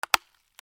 古びた木片 物音
/ M｜他分類 / L01 ｜小道具 /
『カタン』